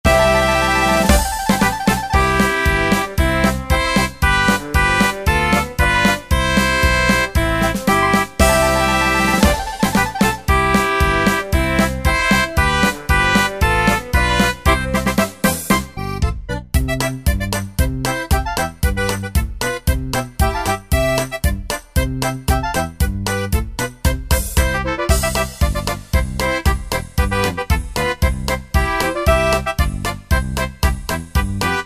Tempo: 115 BPM.
MP3 with melody DEMO 30s (0.5 MB)zdarma